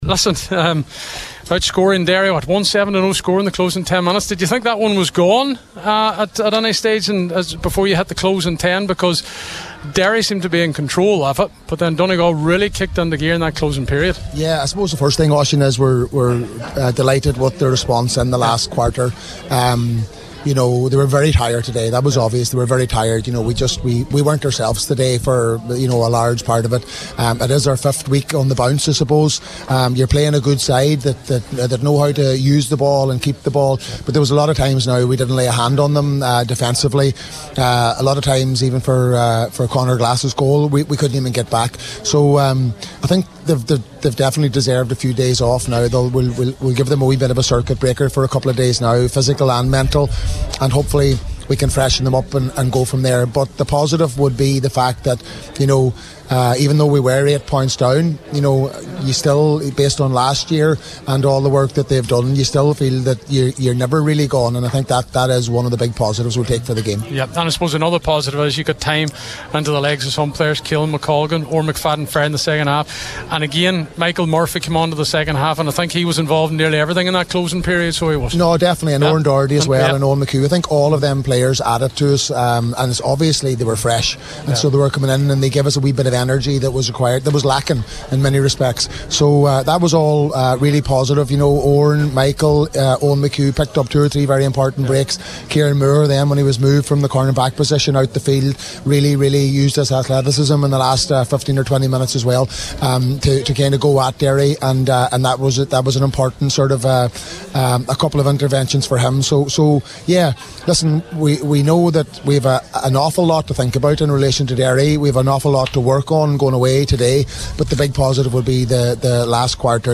after today’s game